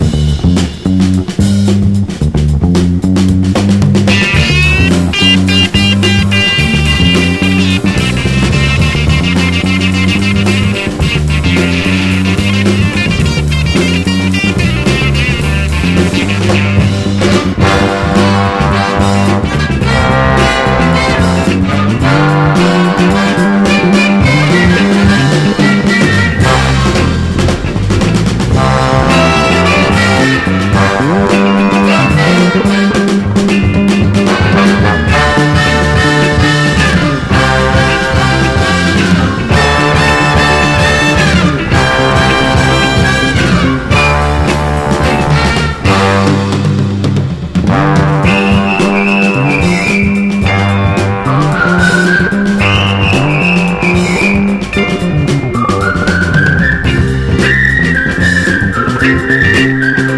/ POST PUNK.
ほとんどスウェル・マップス直系な、へなちょこローファイ・ガレージ・サウンドがカッコ良過ぎます！